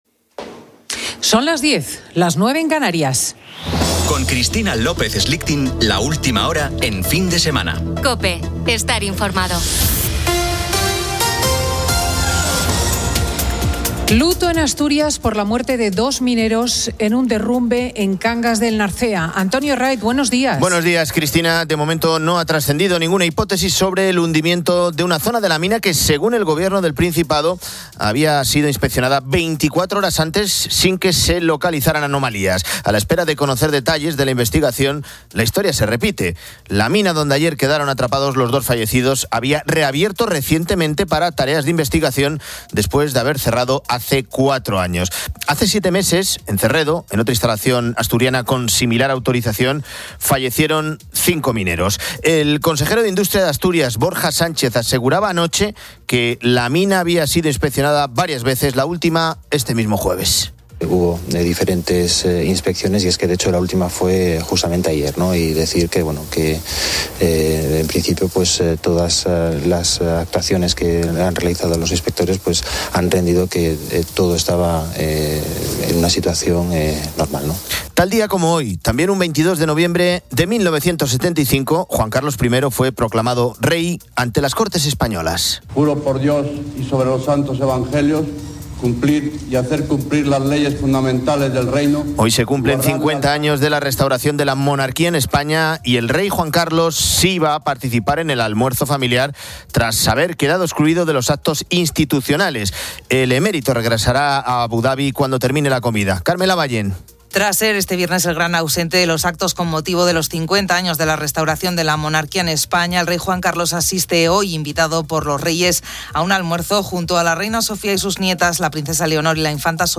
Fin de Semana 10:00H | 22 NOV 2025 | Fin de Semana Editorial de Cristina López Schlichting.
Francisco Rodríguez, el presidente de la Diputación de Granada, desde el castillo de La Calahorra, donde se está emitiendo el programa Este contenido ha sido creado por el equipo editorial con la asistencia de herramientas de IA.